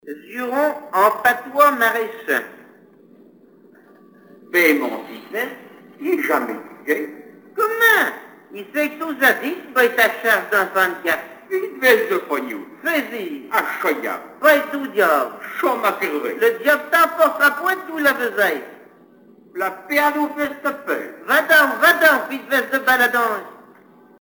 Jurons maraîchins
Genre formulette